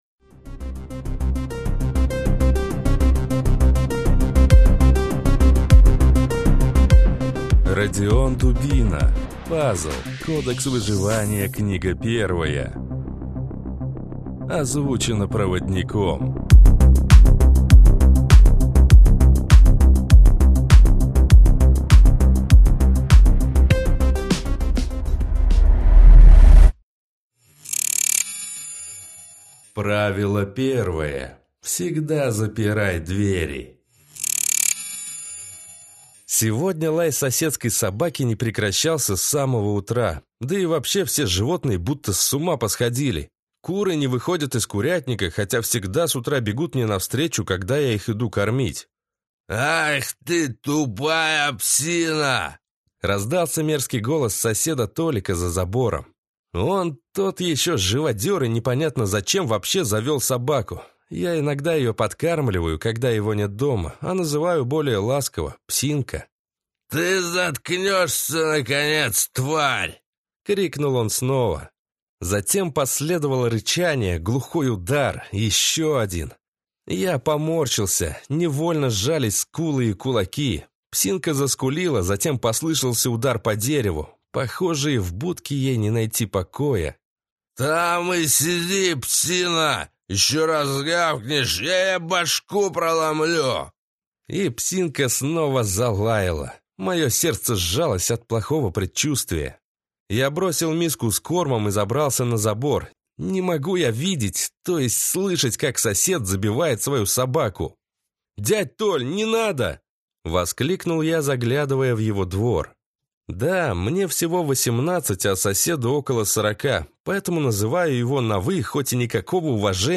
Аудиокнига Кодекс выживания | Библиотека аудиокниг
Прослушать и бесплатно скачать фрагмент аудиокниги